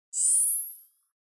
rangefinderzoomout.mp3